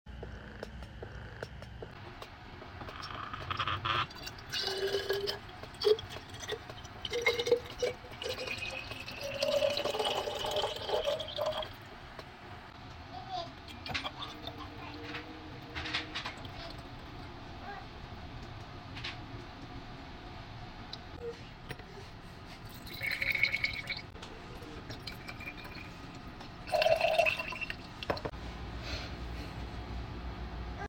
Another full pump and pour sound effects free download